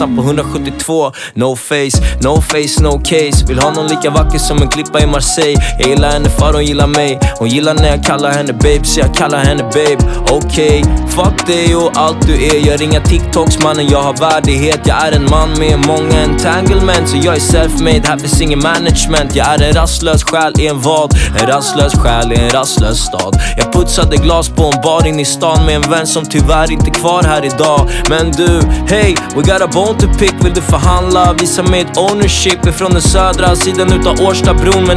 2025-03-28 Жанр: Поп музыка Длительность